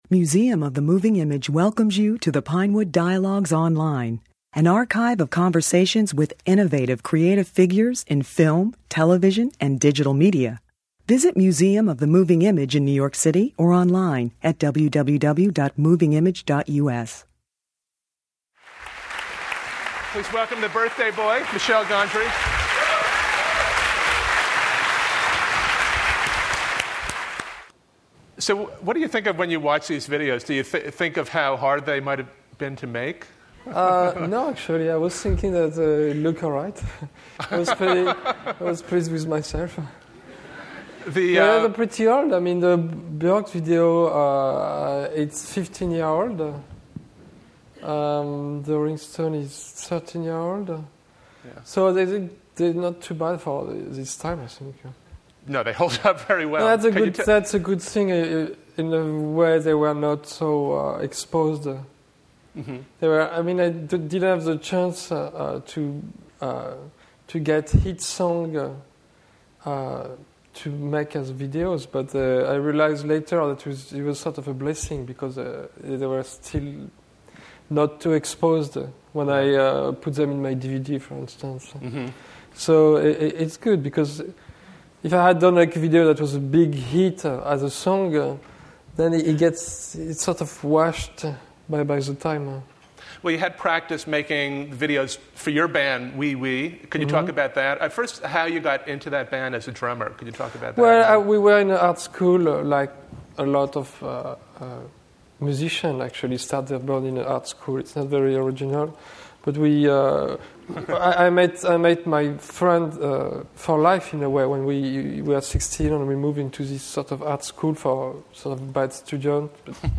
Download Dialogue (MP3) Download Transcript (PDF) Read Transcript (HTML)